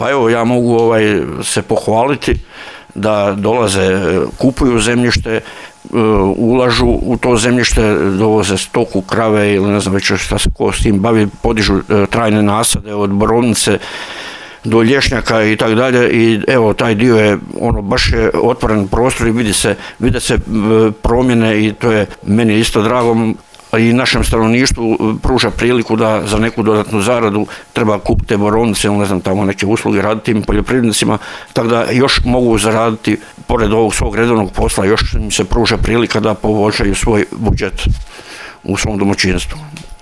O zemljištima, novim ulaganjima i projektima te trenutnoj situaciji vezanoj uz poljodjelstvo na području ove općine govori načelnik Grga Dragičević